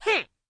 Cat Attack Sound Effect
Download a high-quality cat attack sound effect.
cat-attack-1.mp3